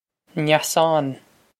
Neasán Nyass-ahn
This is an approximate phonetic pronunciation of the phrase.